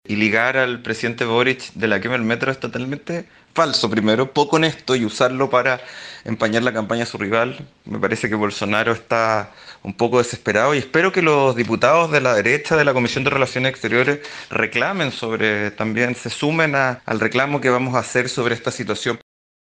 El diputado socialista y miembro de la Comisión de Relaciones Internacionales de la Cámara Baja, Tomás de Rementería, anunció que solicitará al comité el envío de un oficio a Cancillería para solicitar explicaciones al gobierno brasileño.